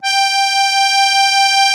MUSETTE 1.14.wav